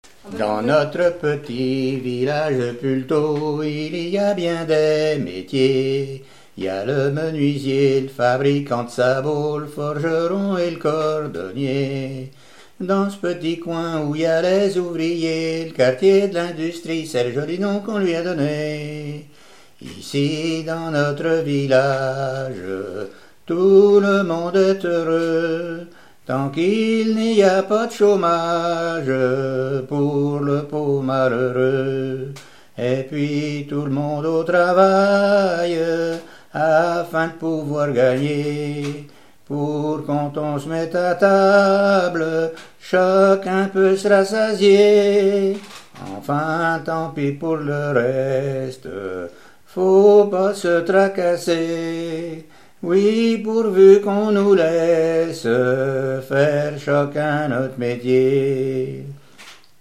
Enquête Arexcpo en Vendée-Pays Sud-Vendée
Pièce musicale inédite